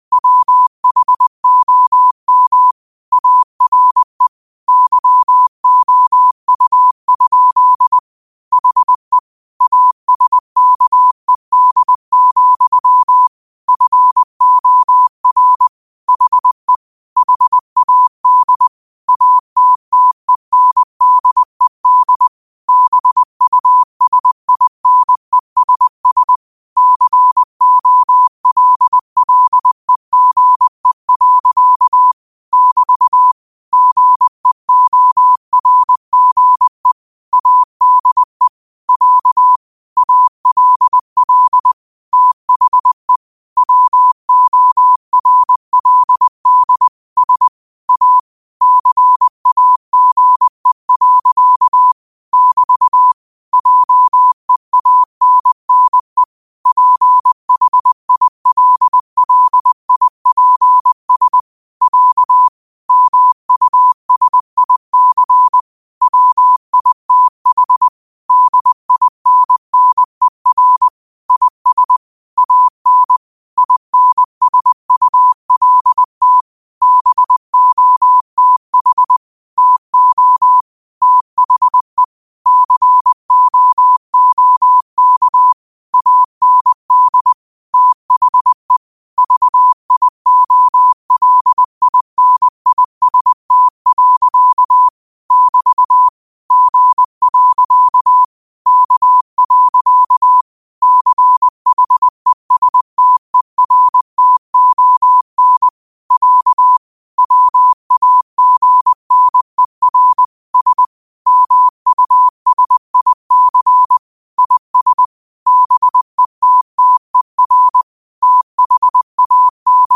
Quotes for Sat, 16 Aug 2025 in Morse Code at 20 words per minute.